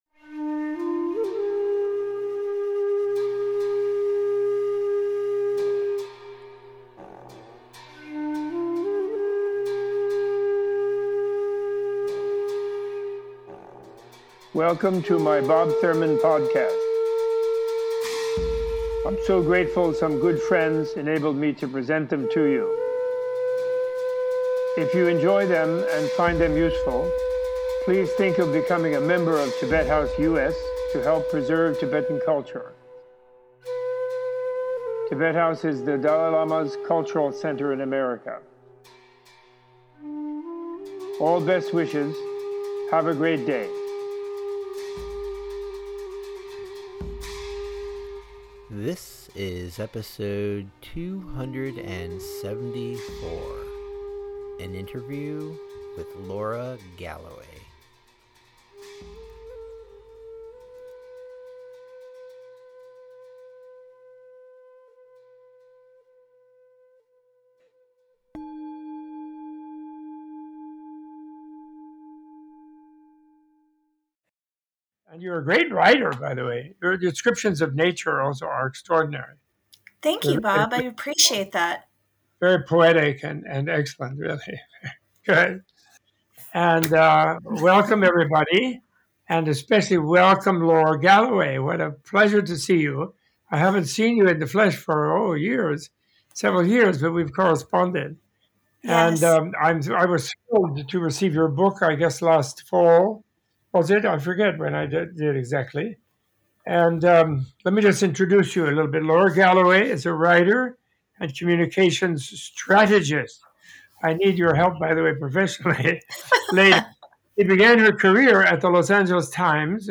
Tibet House US Menla Conversation